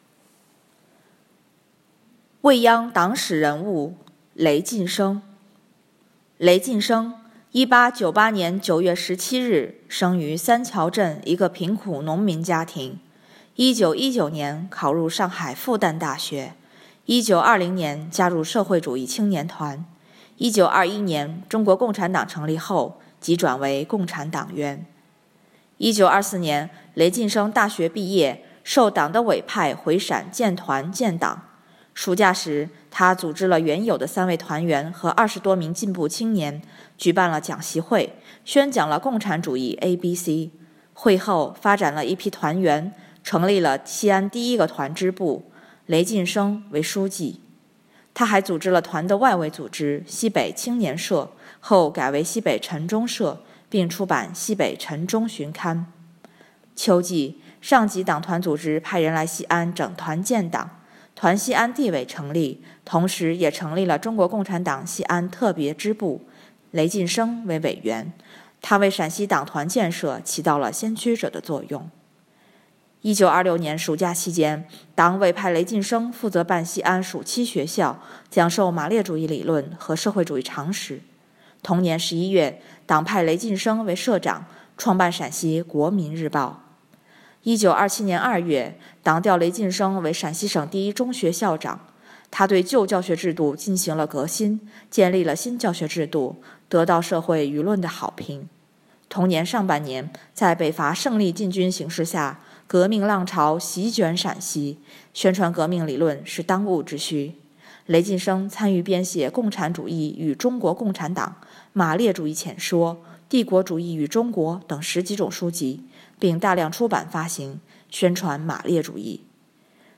革命烈士雷晋笙.mp3 该诵读录音内容，摘自未央区档案馆馆藏1984年6月27日《陕西日报》第三版，主要介绍了中共党员、革命烈士雷晋笙的生平情况。 雷晋笙是陕西党团建设的先驱者，促成了西安第一个团支部的成立，历任中国共产党西安特别支部委员、陕西国民日报社社长、陕西省第一中学校长、河南省委秘书长、山东省委书记。